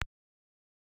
click2.ogg